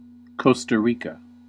Ääntäminen
Ääntäminen US UK : IPA : /ˌkɒs.tə ˈɹiː.kə/ US : IPA : /ˌkoʊ.stə ˈɹi.kə/ IPA : /ˌkɑ.stə ˈɹi.kə/ Lyhenteet CR Haettu sana löytyi näillä lähdekielillä: englanti Käännös Erisnimet 1. Costa Rica Määritelmät Erisnimet A country in Central America .